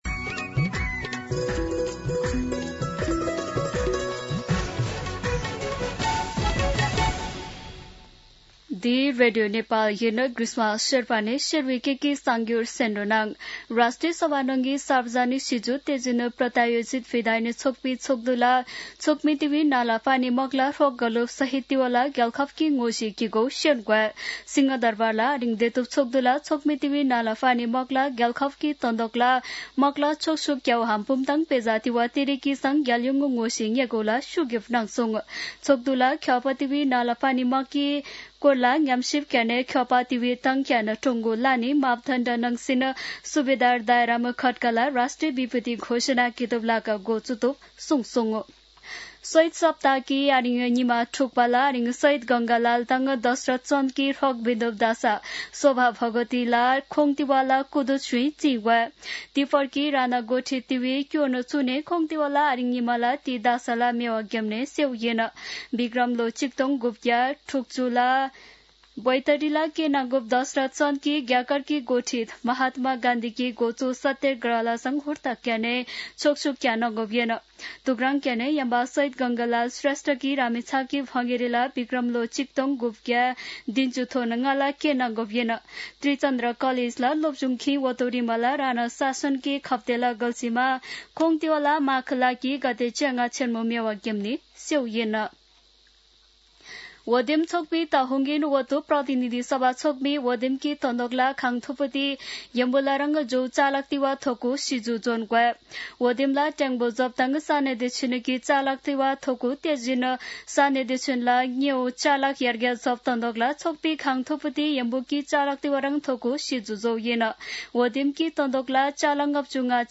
शेर्पा भाषाको समाचार : १५ माघ , २०८२
Sherpa-News-10-15.mp3